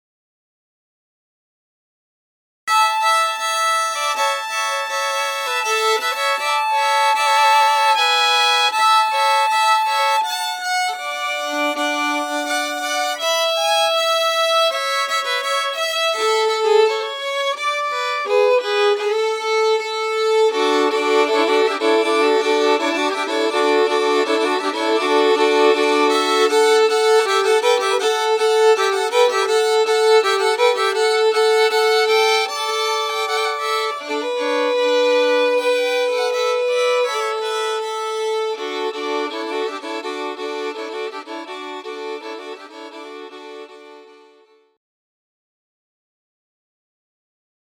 Tune #2(.mp3 file) is what we usually use for the recessional at the end of the ceremony